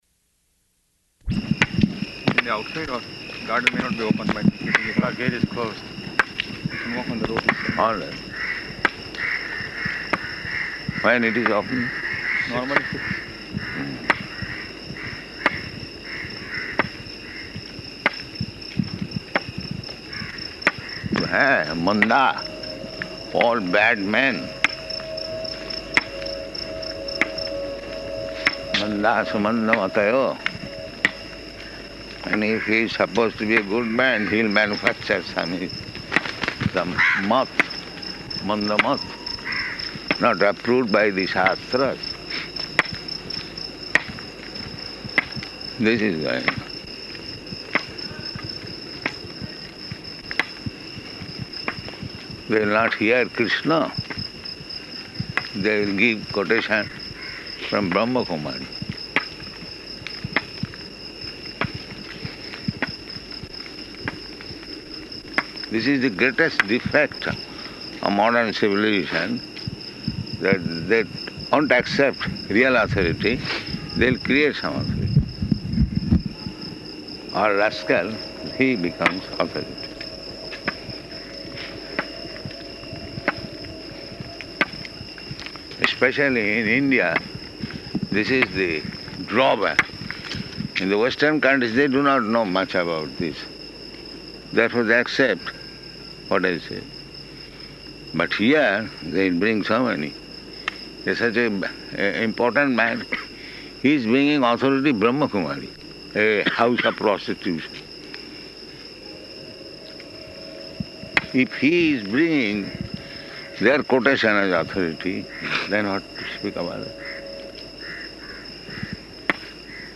-- Type: Walk Dated: September 29th 1975 Location: Ahmedabad Audio file